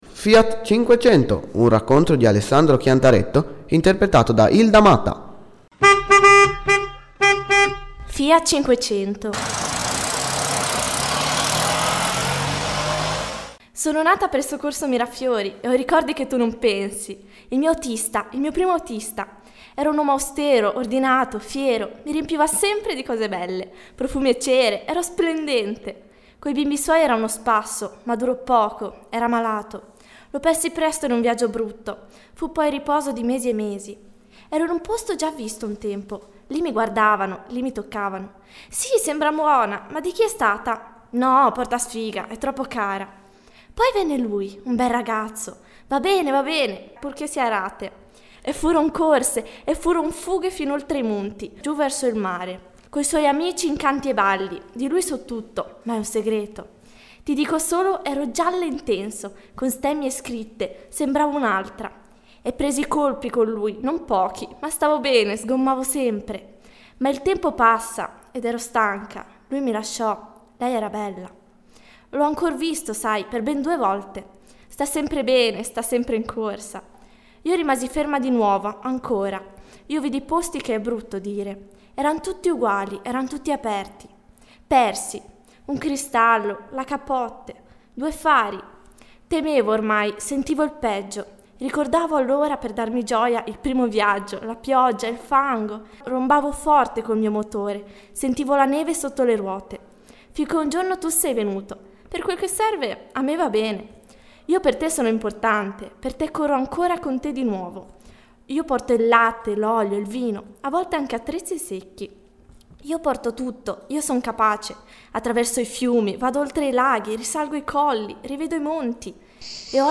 arricchito da sfondi sonori per ambientazione. Questa è la versione "flat", così come era negli intenti dell'autore, recitata da una voce femminile (la 500) nel silenzio.